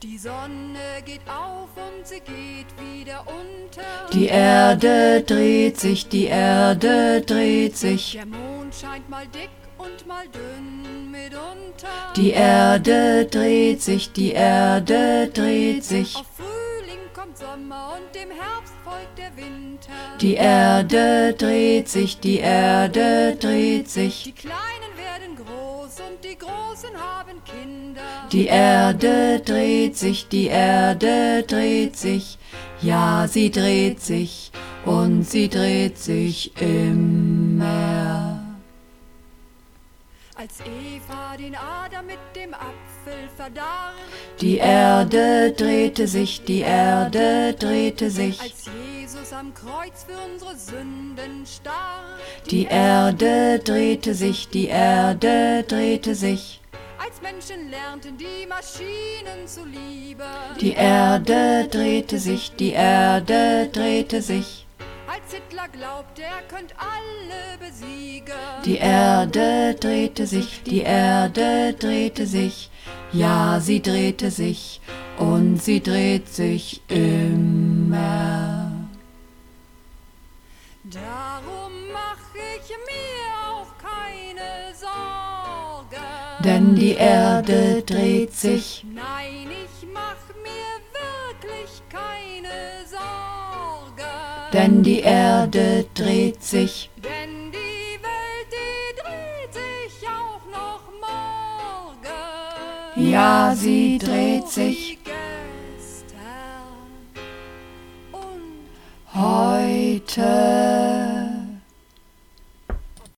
Übungsaufnahmen - Immer
Immer (Mehrstimmig)